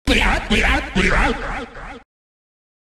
Gerson-Old-Man-Laughing-Sound-Effect-Deltarune-Chapter-4.mp3